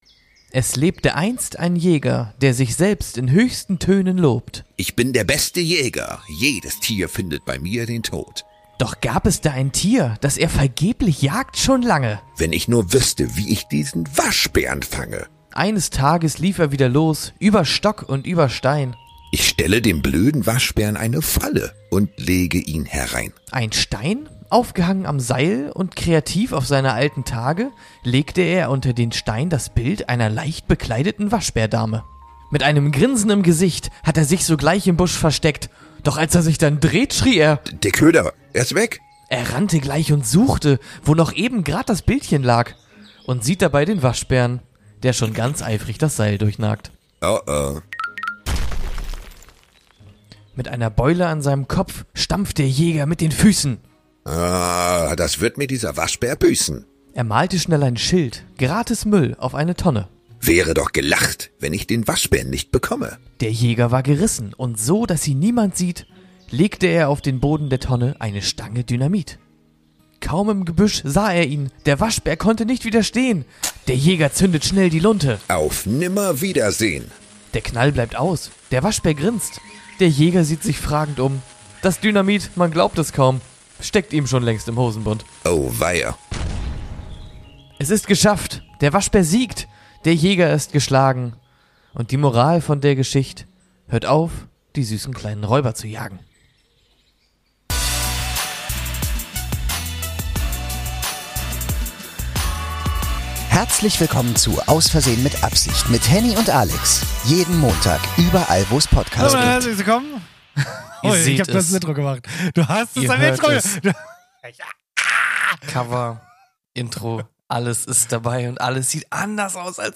Wie ihr es von uns erwartet, gibt es auch diese Woche viel zu Lachen, jede Menge gute Laune und selbstverständlich ein Quiz.